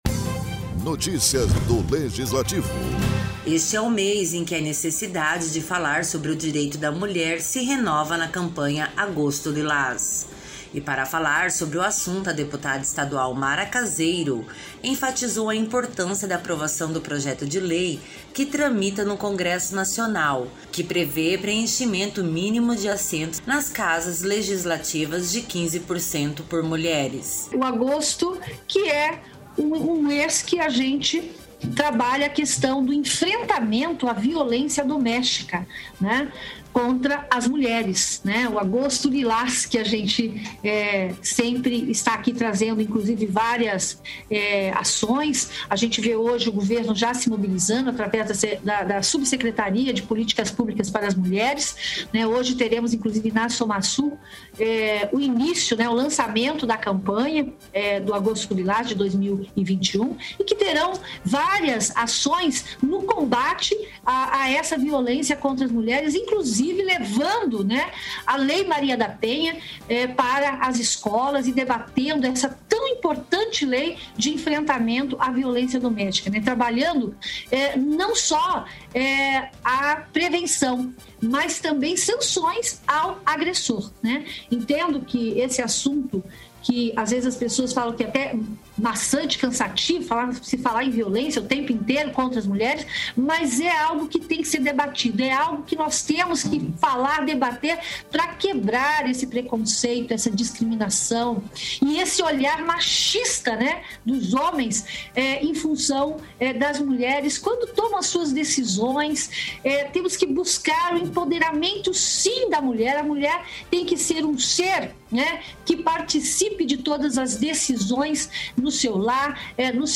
Durante a sessão plenária desta terça-feira (03), a deputada estadual Mara Caseiro, do PSDB, enfatizou a importância da aprovação do projeto de lei que tramita no Congresso Nacional, que prevê preenchimento mínimo de assentos nas Casas Legislativas de 15% por mulheres.